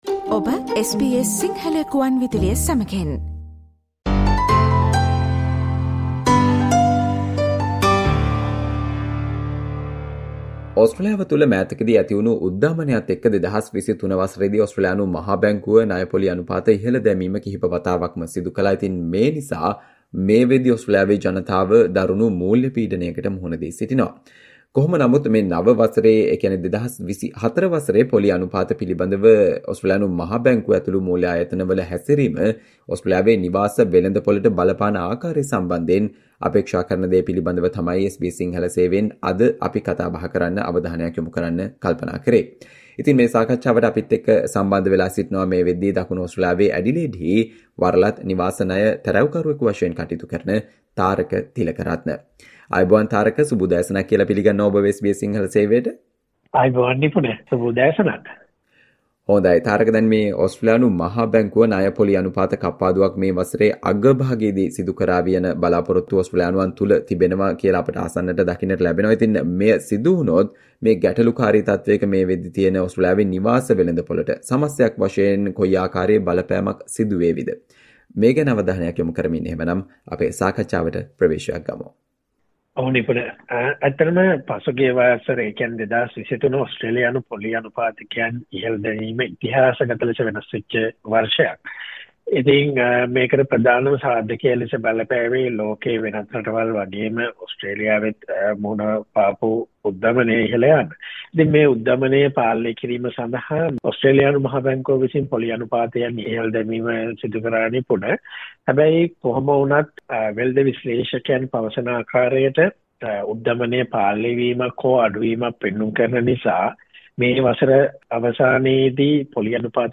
SBS Sinhala discussion on what to expect in Australia's housing market in 2024